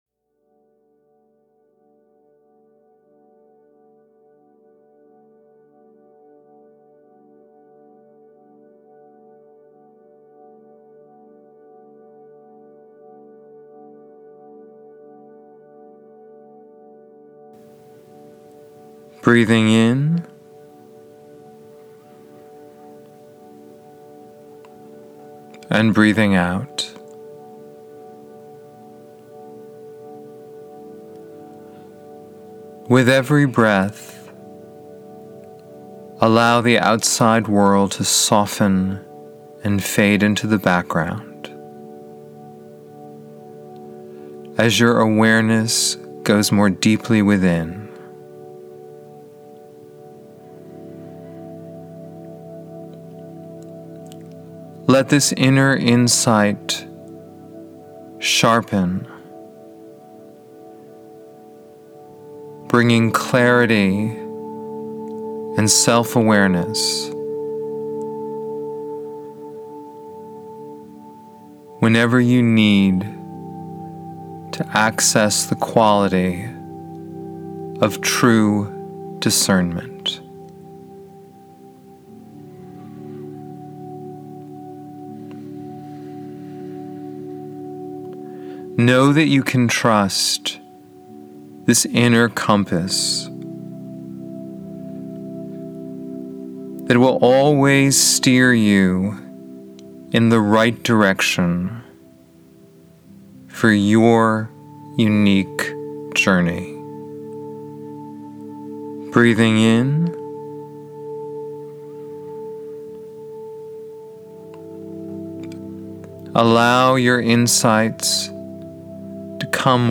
Discernment-Meditation.mp3